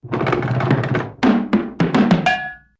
File:Objects Roll Fall CRT043401.oga
Sound effect used in Donkey Kong Country 2 (Game Boy Advance) and Donkey Kong Country 3 (Game Boy Advance).